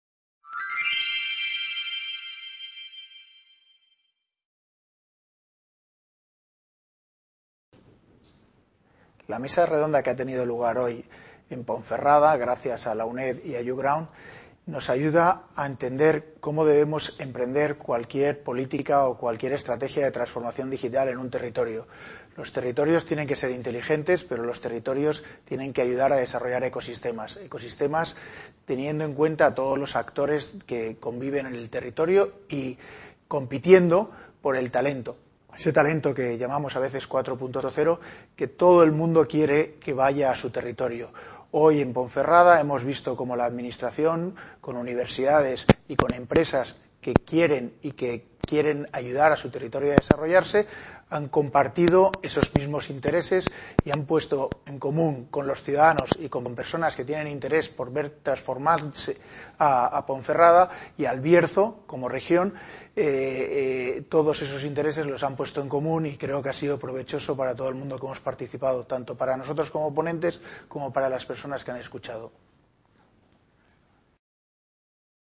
VI Edición del Festival Villar de los Mundos - El PASADO de LOS BARRIOS y el FUTURO de EL BIERZO
Video Clase